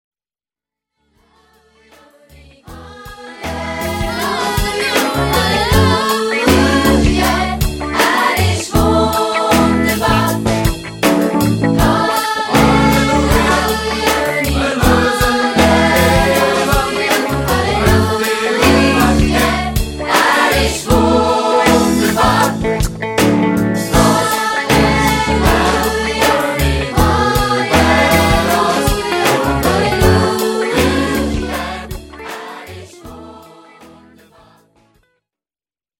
...Chor